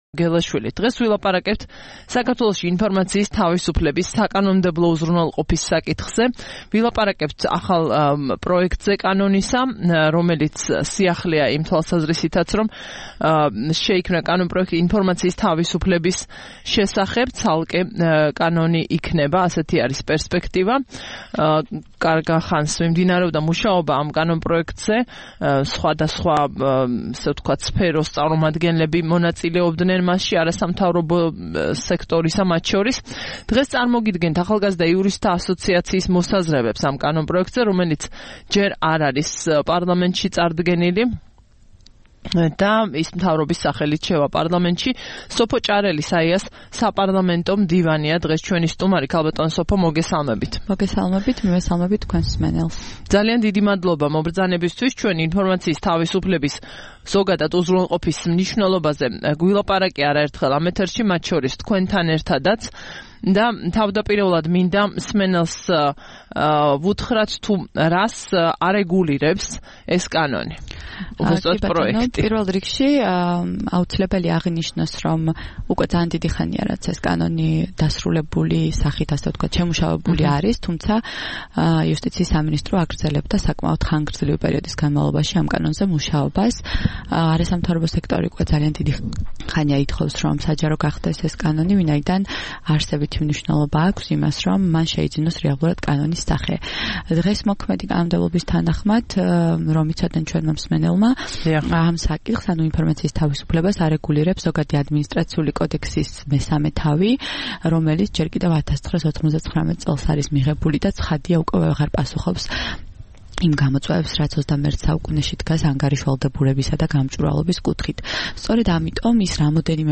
25 მაისს რადიო თავისუფლების "დილის საუბრების" სტუმარი იყო